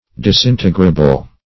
Disintegrable \Dis*in"te*gra*ble\, a.